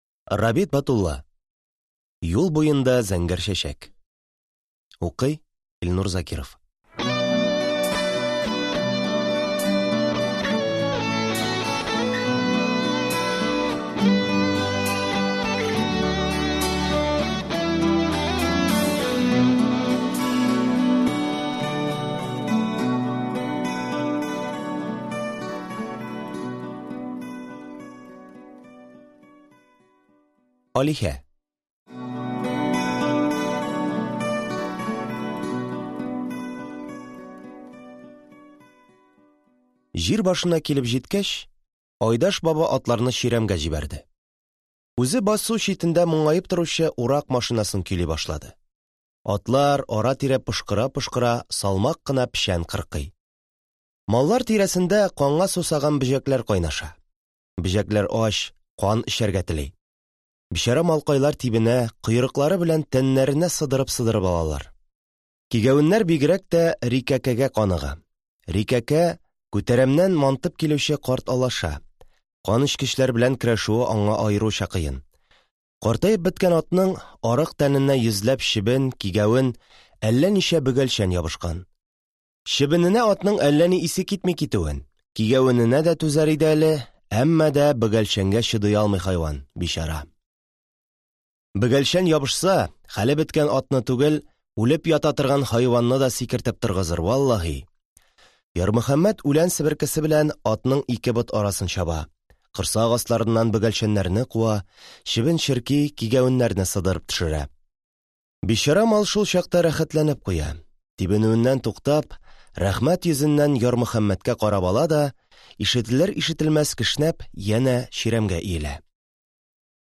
Аудиокнига Юл буенда зәңгәр чәчәе | Библиотека аудиокниг